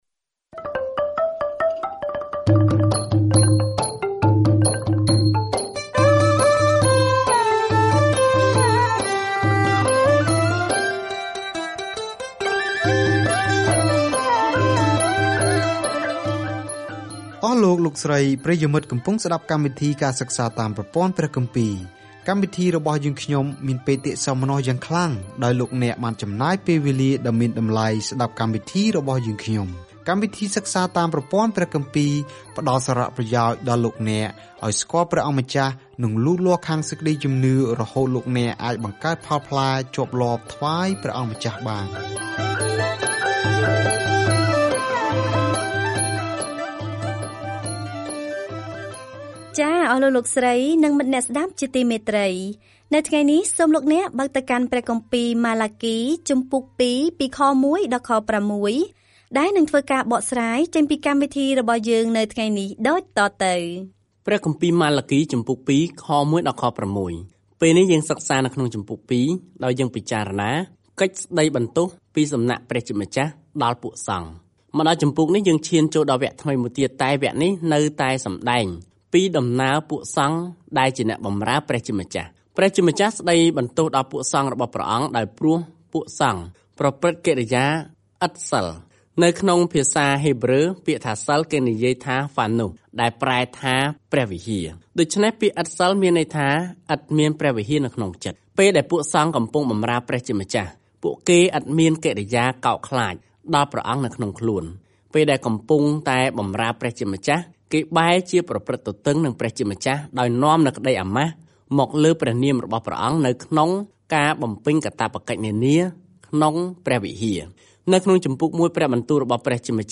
ម៉ាឡាគីរំឭកជនជាតិអ៊ីស្រាអែលដែលផ្តាច់ទំនាក់ទំនងថាគាត់មានសារពីព្រះមុនពេលពួកគេស៊ូទ្រាំនឹងភាពស្ងៀមស្ងាត់ដ៏យូរ - ដែលនឹងបញ្ចប់នៅពេលដែលព្រះយេស៊ូវគ្រីស្ទចូលដល់ឆាក។ ការធ្វើដំណើរជារៀងរាល់ថ្ងៃតាមរយៈម៉ាឡាគី នៅពេលអ្នកស្តាប់ការសិក្សាជាសំឡេង ហើយអានខគម្ពីរដែលជ្រើសរើសពីព្រះបន្ទូលរបស់ព្រះ។